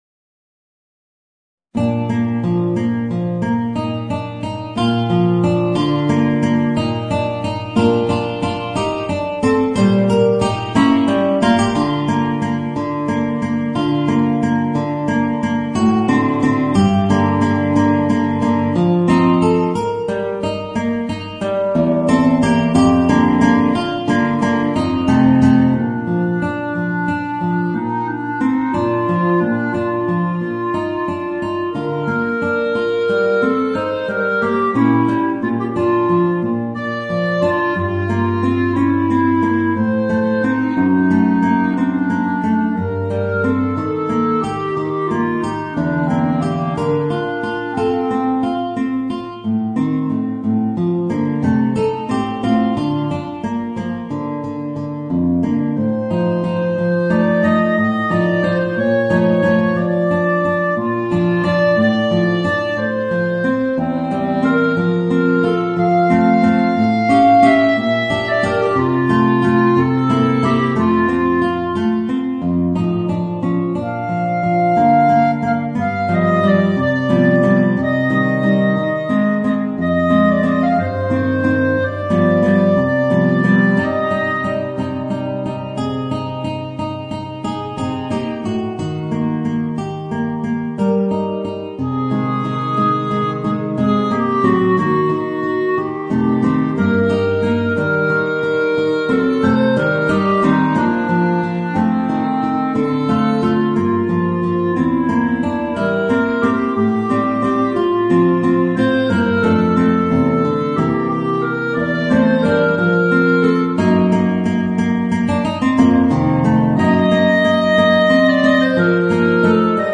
Voicing: Guitar and Clarinet